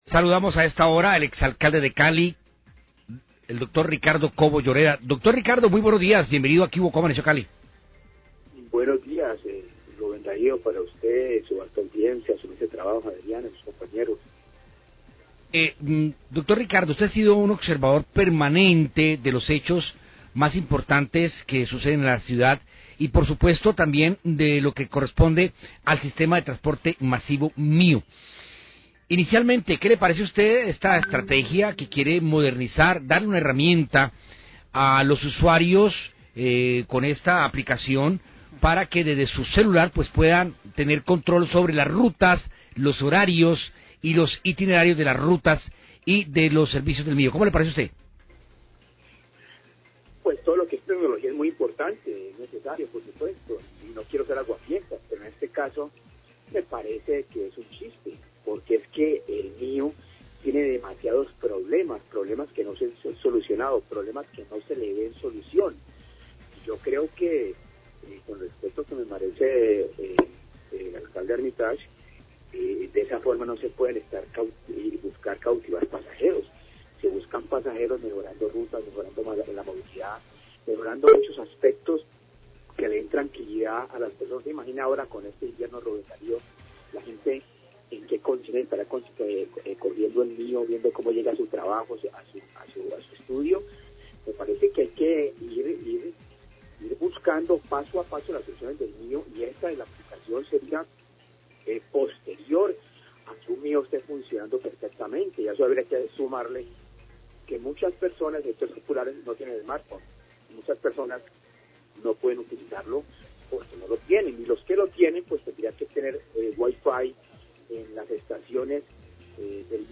Radio
El Gobierno caleño anunció que creará un fondo transitorio por 40 mil millones de pesos para ayudar en la operación de los buses del sistema de transporte masivo, MIO. Ex alcalde Ricardo Cobo, habla acerca de este tema, expone que el sistema sigue con problemas y se cuestiona de donde salen estos recursos y para dónde van.